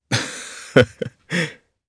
Chase-Vox_Happy2_jp.wav